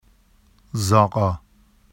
[zɑɢɑ] n stable located underground